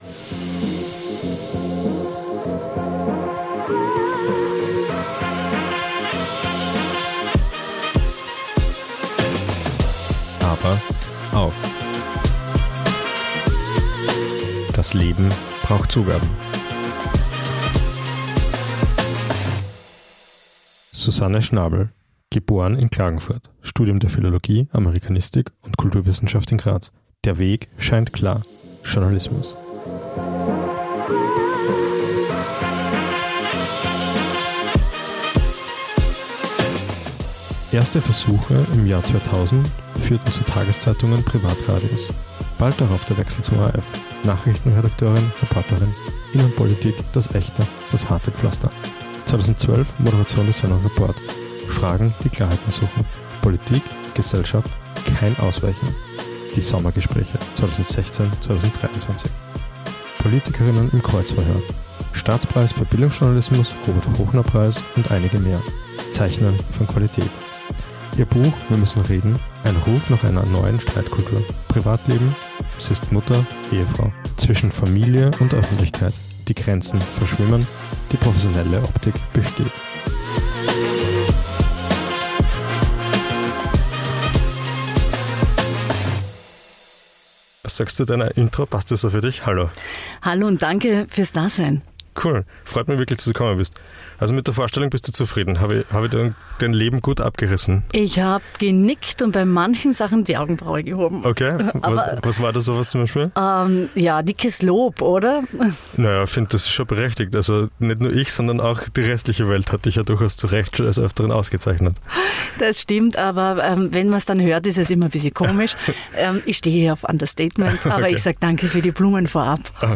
Einmal mehr hat es uns mit dem PODMOBIL nach Wien verschlagen.
Direkt am Küniglberg, genauer gesagt am Parkplatz des ORF hat uns Susanne Schnabl zum Gespräch empfangen. Sie hat uns von ihrem Leben als Journalistin erzählt, warum sie in Wirklichkeit nie offline ist ABER AUCH warum sich die Profi-Karriere im Basketball zeitlich (und wohl auch größentechnisch) nicht ausgegangen ist.